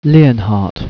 The British Pronounciation
Lee-on-hart (wave file, 9K long)